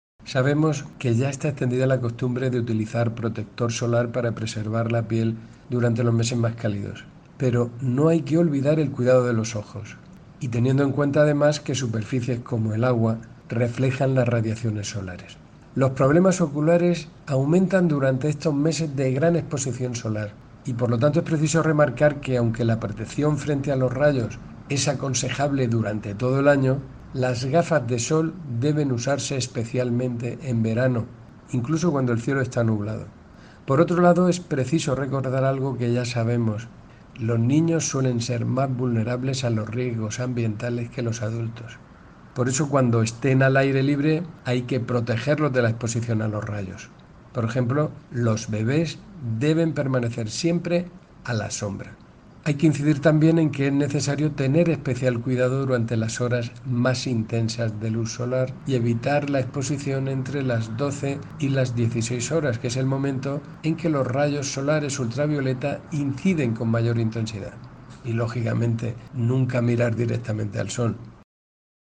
Declaraciones del director general de Salud Pública y Adicciones, José Jesús Guillén, sobre las recomendaciones para protegerse de la radiación solar